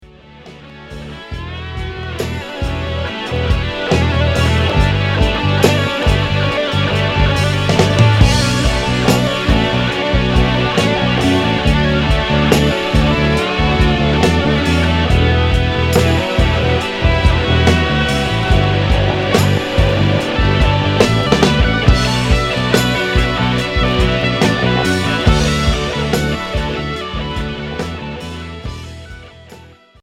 Hard progressif Unique 45t retour à l'accueil